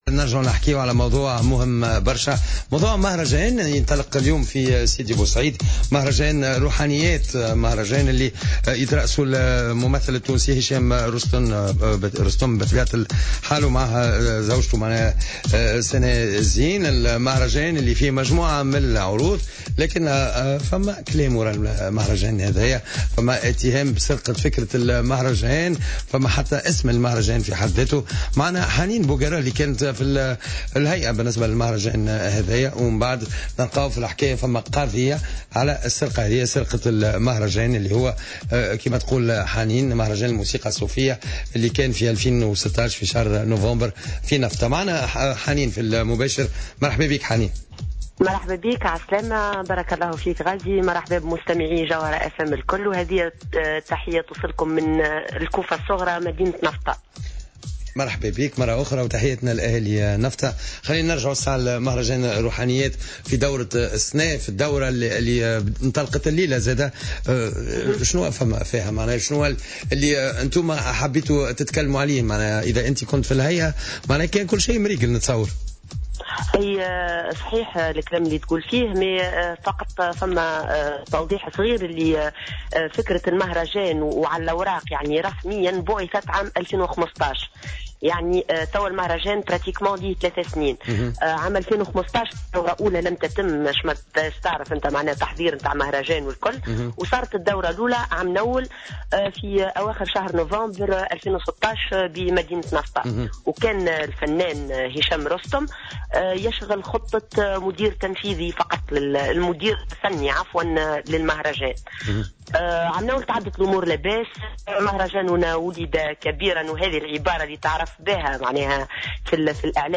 خلال مداخلة هاتفية لها في برنامج "jawhara by night"